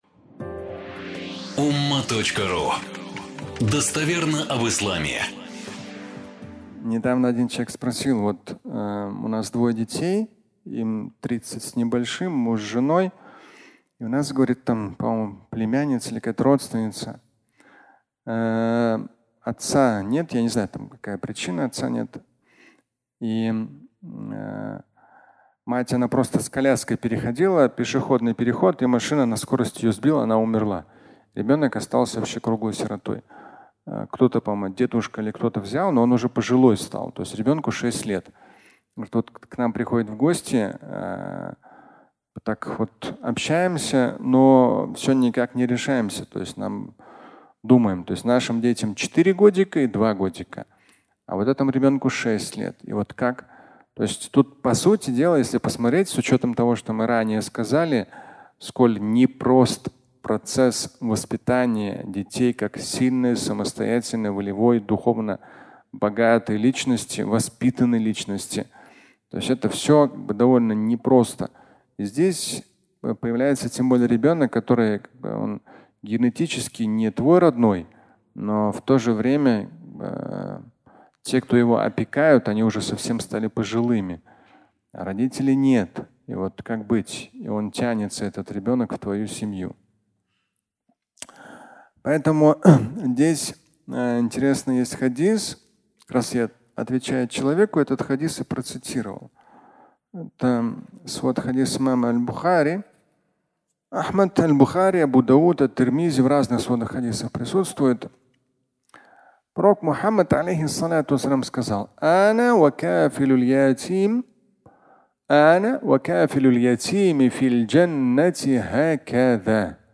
Сирота (аудиолекция)